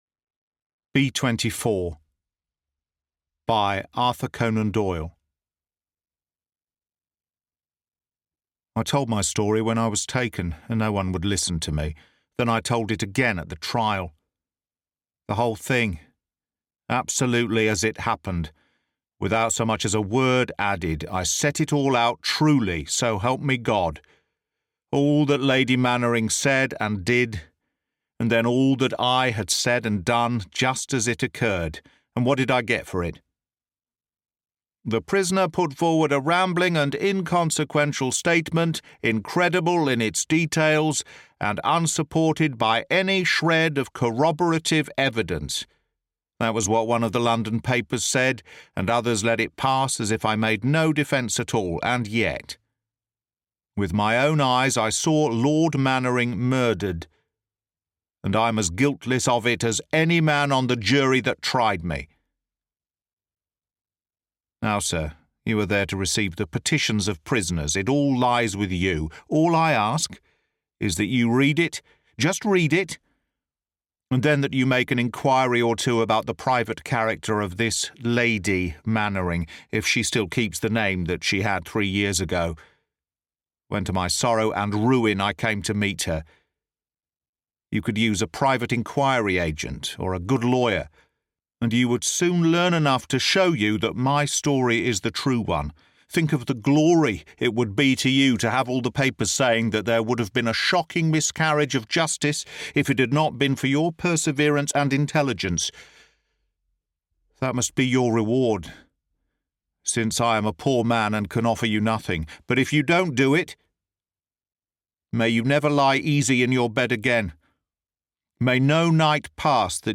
Sherlock Holmes’ Darkest Secret: A Case That Will Haunt You (Audiobook)